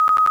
notify.wav